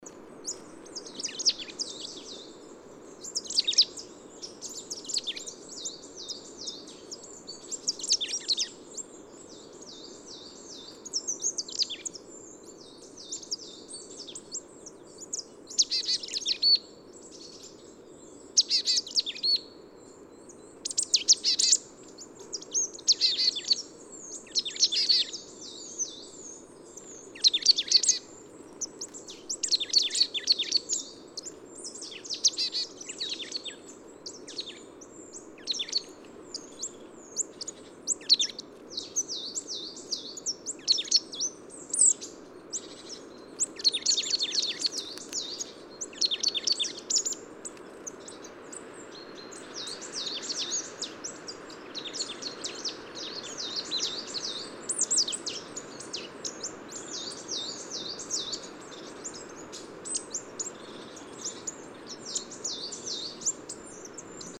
PFR07504, 130204, Marsh Tit Poecile palustris, song, take-off call, counter song